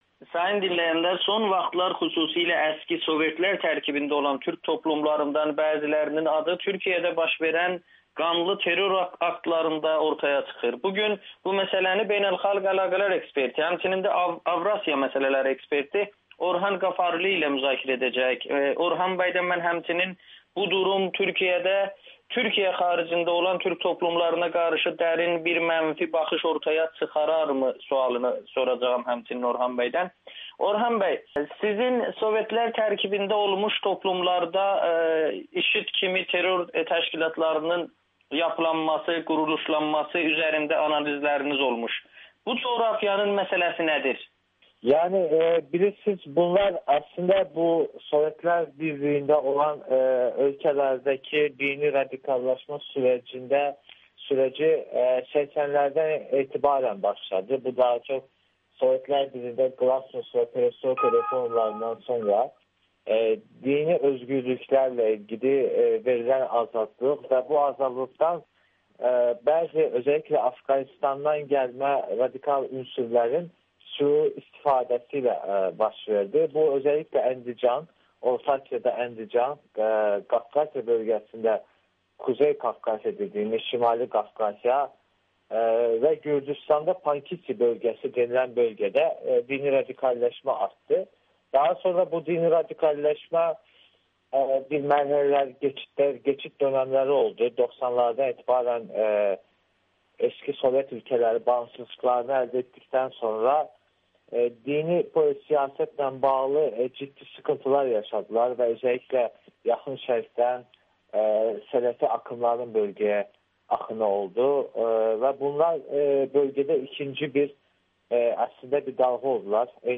müsahibədə